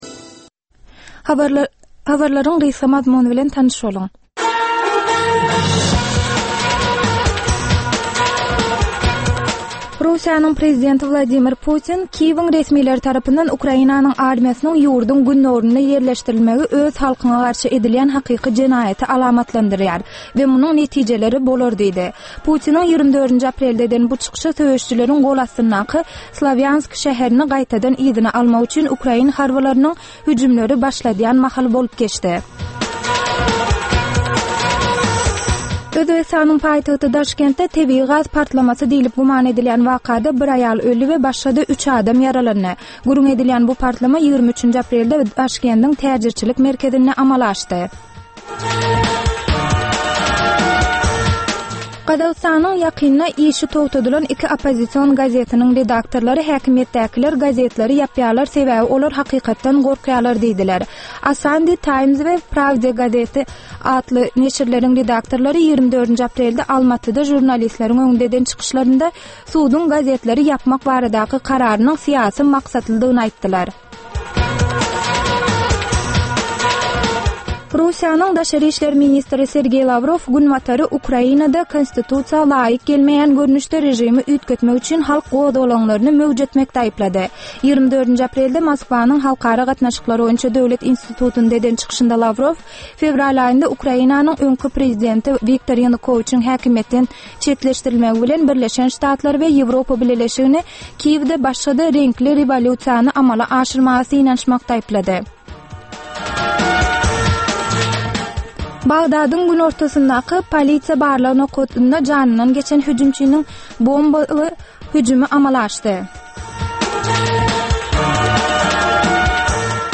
Türkmenistanly synçylaryň gatnaşmagynda, ýurduň we halkyň durmuşyndaky iň möhüm meseleler barada töwerekleýin gürrüň edilýän programma. Bu programmada synçylar öz pikir-garaýyşlaryny aýdyp, jedelleşip bilýärler.